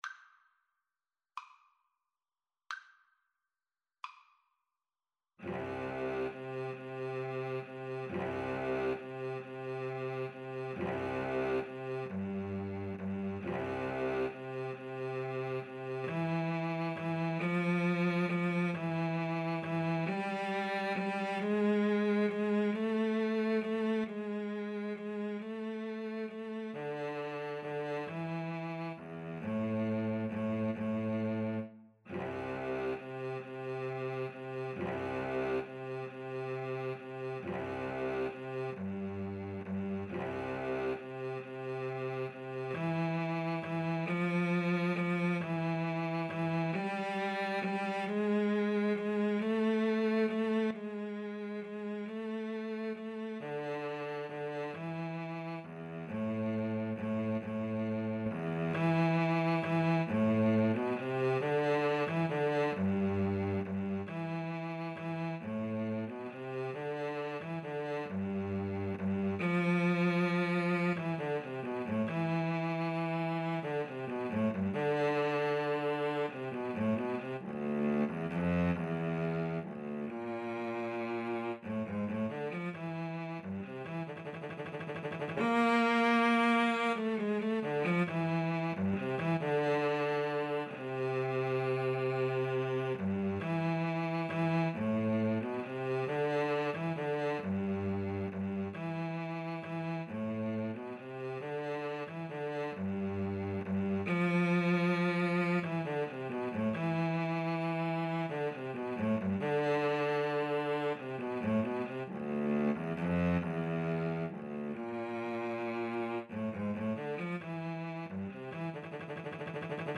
Andantino .=c.45 (View more music marked Andantino)
6/8 (View more 6/8 Music)
Cello Duet  (View more Intermediate Cello Duet Music)
Classical (View more Classical Cello Duet Music)